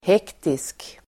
Ladda ner uttalet
Uttal: [h'ek:tisk]
hektisk.mp3